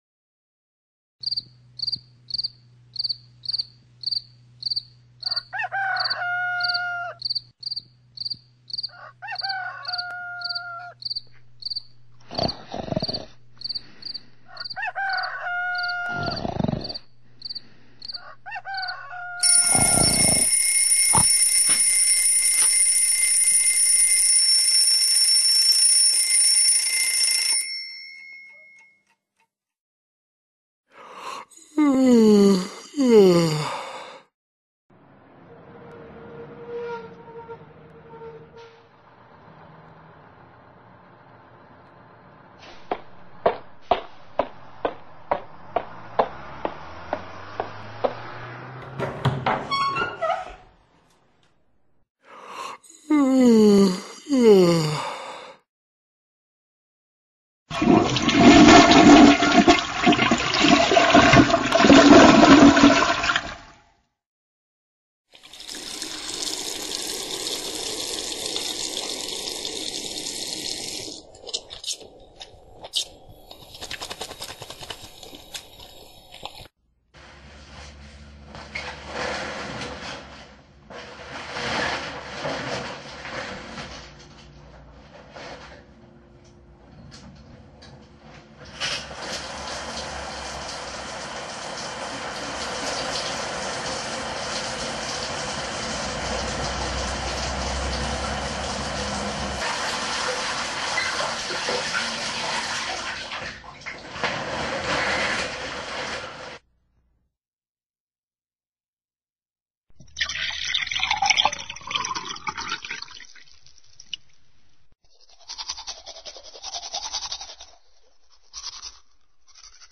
Una cadena de sonidos también permite trabajar la descripción de hechos o secuencias.
sonidos-un-día.mp3